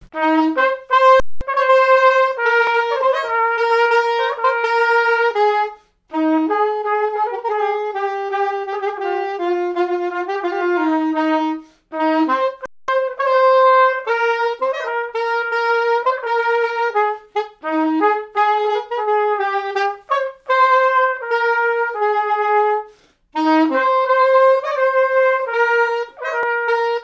Marche nuptiale n° 9
fiançaille, noce
répertoire de marches de noces
Pièce musicale inédite